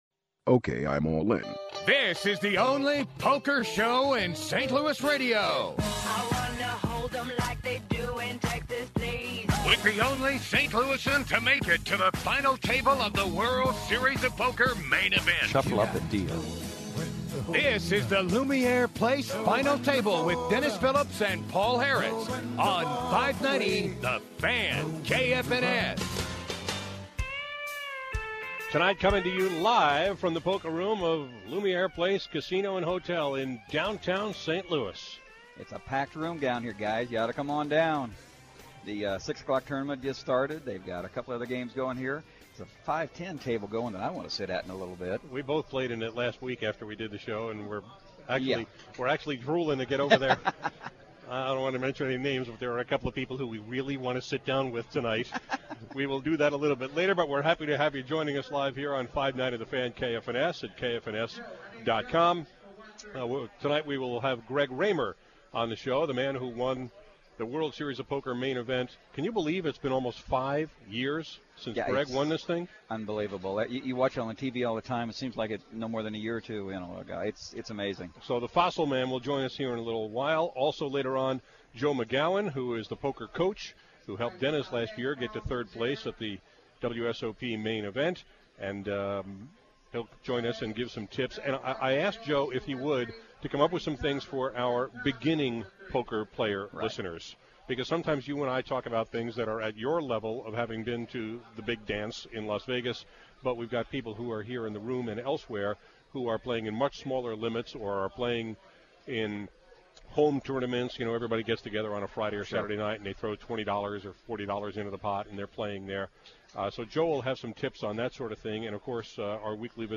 On this week’s installment of The Final Table, the poker radio show I’m doing with Dennis Phillips, we talked with Greg Raymer, who won the 2004 World Series Of Poker Main Event (and then went on to finish 25th in a huge field the next year, too).